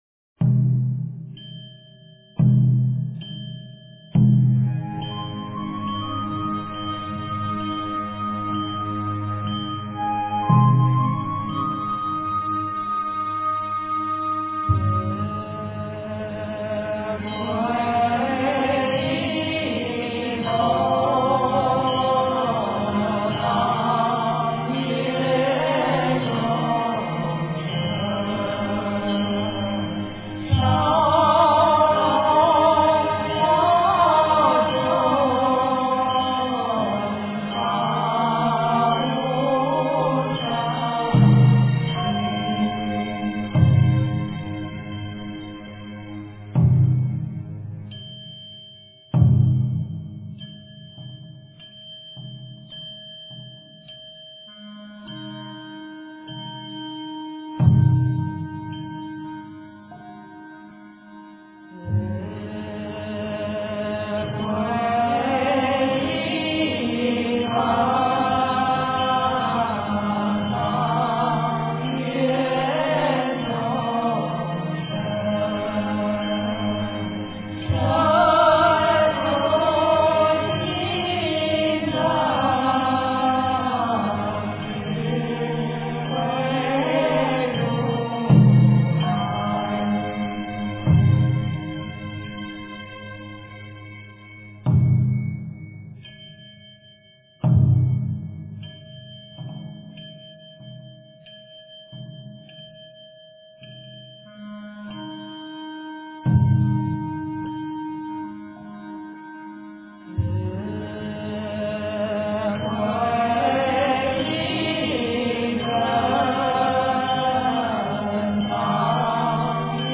佛音 经忏 佛教音乐 返回列表 上一篇： 宝山偈--群星 下一篇： 观音圣号五音调--佛光山 相关文章 大悲咒(功课版